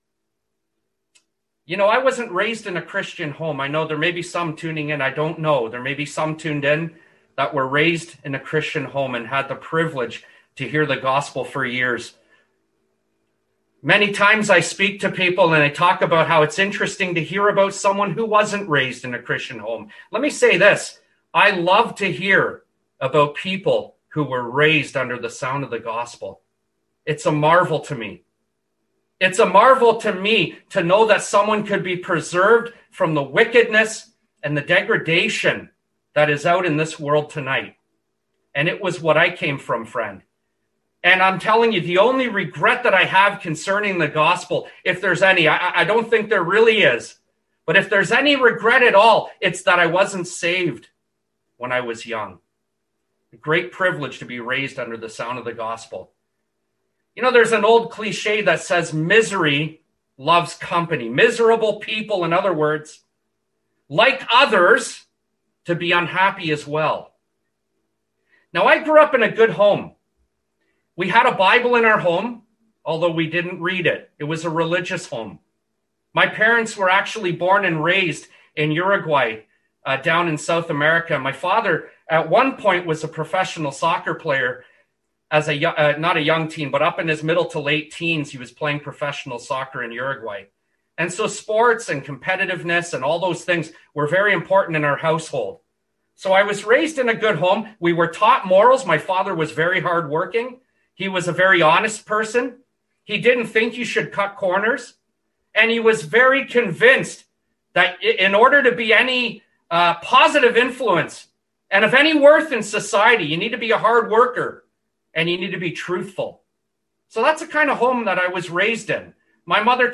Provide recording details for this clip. Readings: Rom 3:19, John 3:16, Rev 20:11-15. (Recorded in Newmarket Gospel Hall, Ontario, Canada, on 21st April 2024)